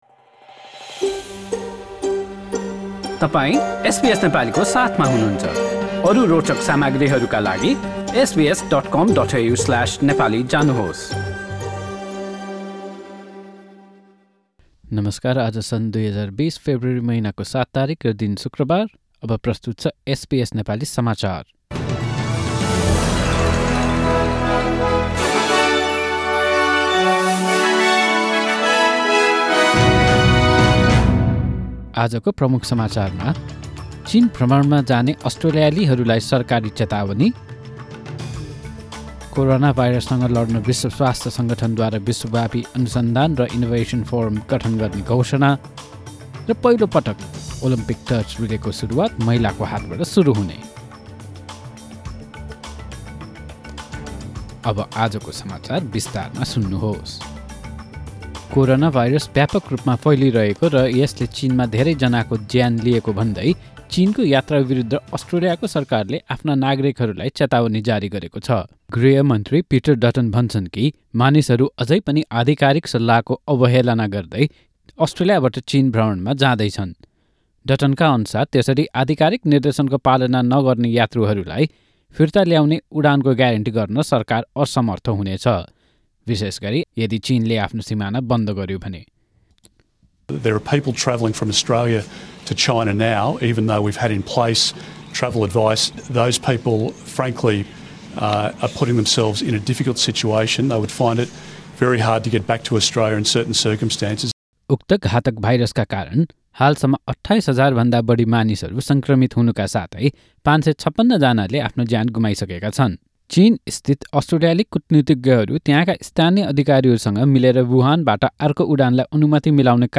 Listen to Home Affairs Minister Peter Dutton says people are still travelling from Australia to China in defiance of official advice in the latest news headlines in Australia from SBS Nepali radio.